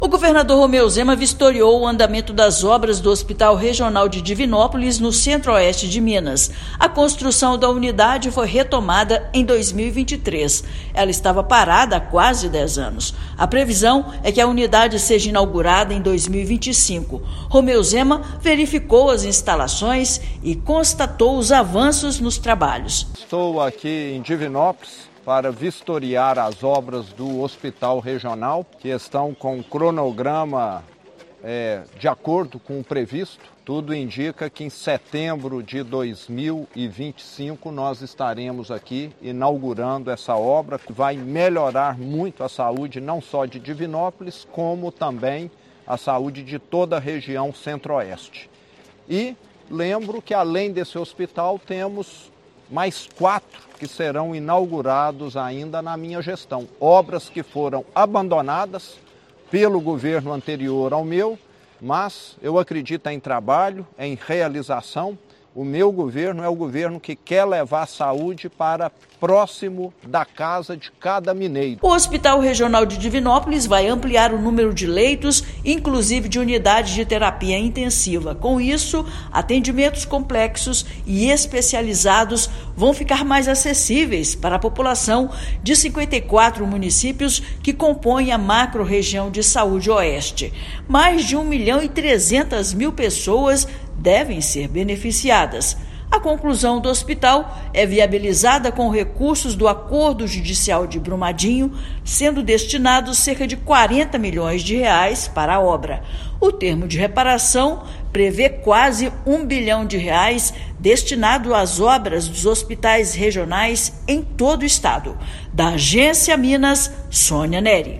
Agência Minas Gerais | [RÁDIO] Governador vistoria avanço das obras do Hospital Regional de Divinópolis
Após a conclusão, unidade vai beneficiar mais de 1,3 milhão de pessoas em 54 municípios mineiros. Ouça matéria de rádio.